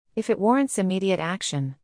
発音：/ˈwɑrənt/